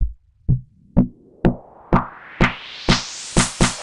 cch_fx_loop_crunch_125.wav